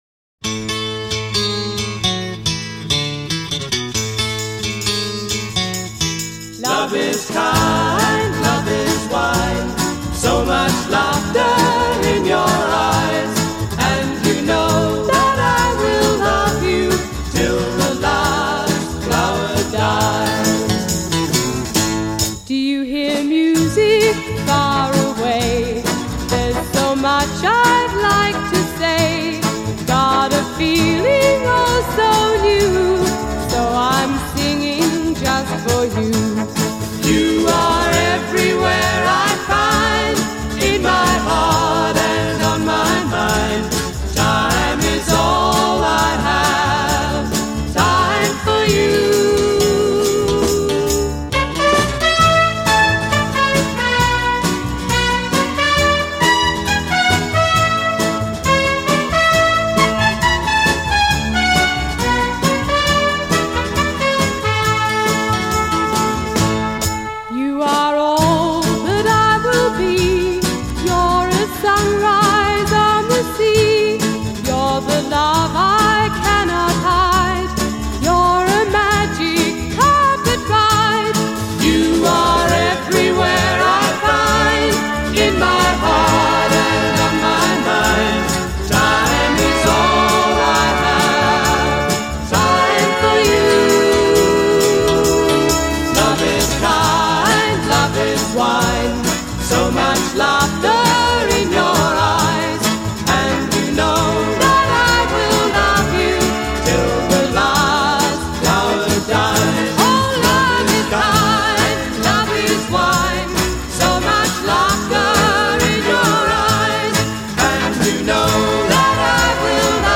crystalline vocals
typically ebullient performance
This joyful tune deserved a wider audience